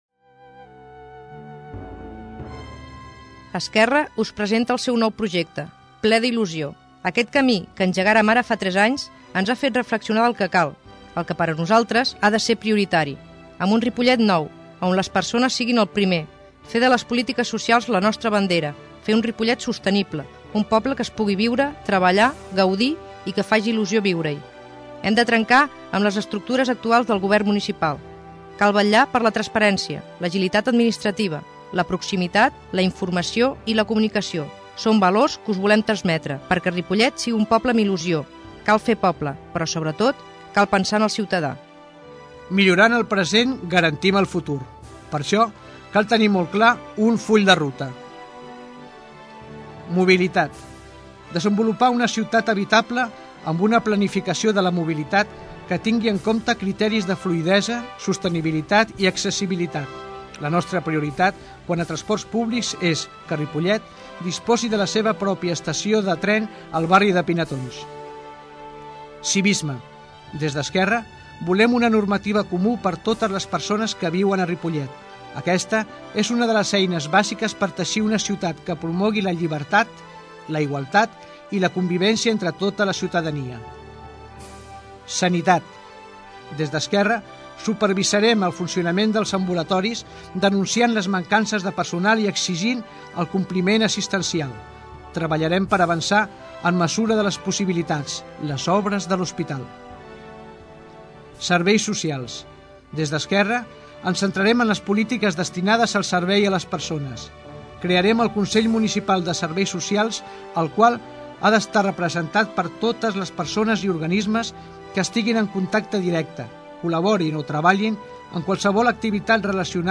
Política MUNICIPALS 2007 - Espai de propaganda d'ERC -Política- 14/05/2007 Descarregueu i escolteu l'espai radiof�nic enregistrats per aquest partit pol�tic de Ripollet a l'emissora municipal.